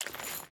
Water Chain Walk 1.ogg